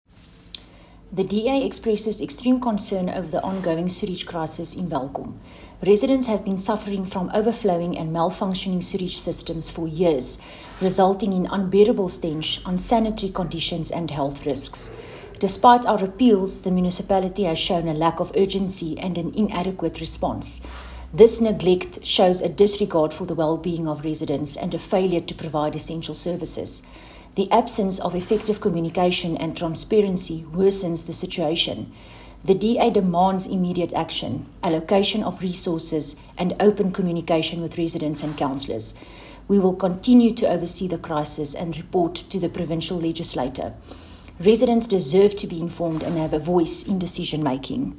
Afrikaans soundbites by Cllr René Steyn.